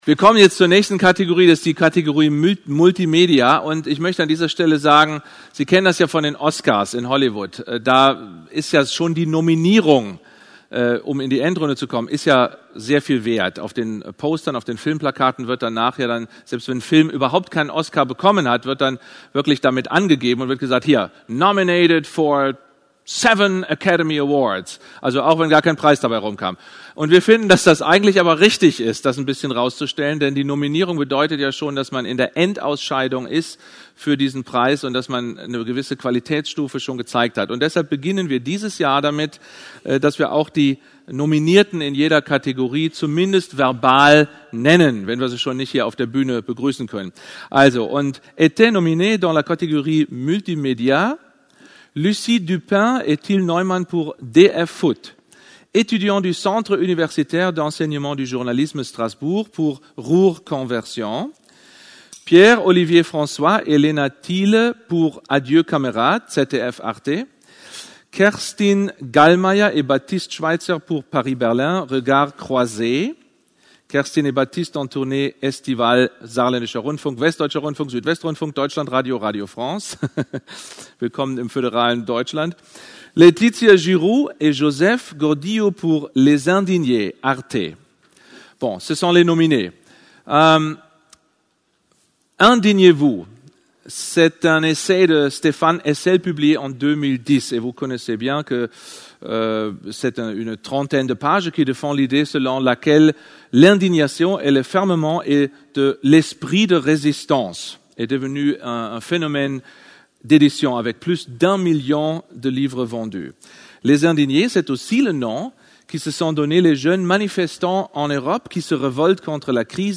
Wo: Berlin, ARD-Hauptstadt-Studio
* Tom Buhrow, Moderator